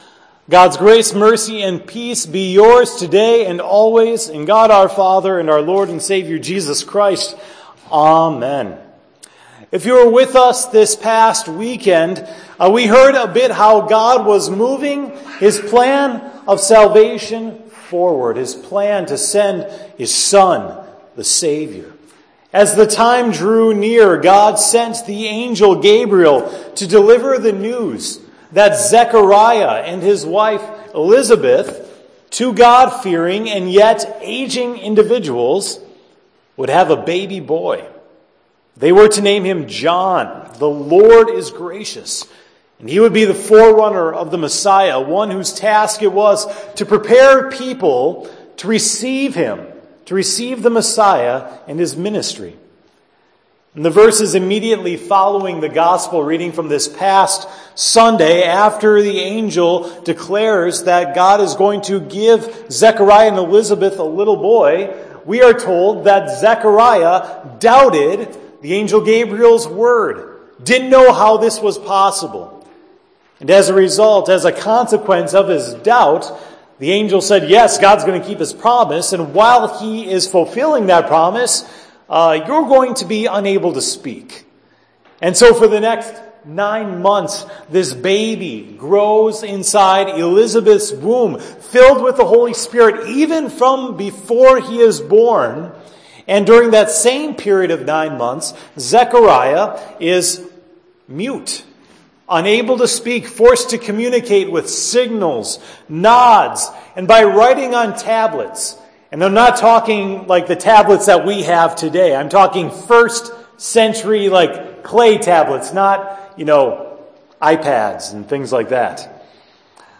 "Zechariah's Song" - Sermon for 12/7 - Hope Lutheran Church